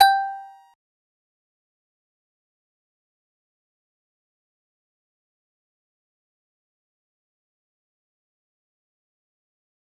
G_Musicbox-G5-pp.wav